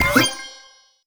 Collectibles_Items_Powerup
collect_item_11.wav